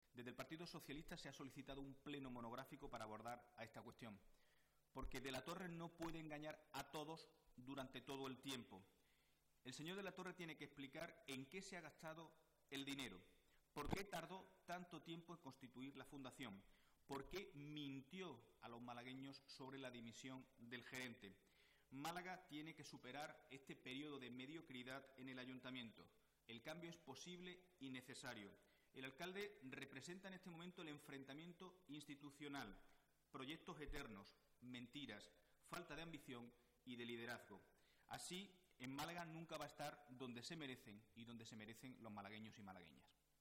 El secretario general del PSOE malagueño, Miguel Ángel Heredia, ha asegurado hoy en rueda de prensa que "tenemos un alcalde que ni da la cara ni asume sus responsabilidades".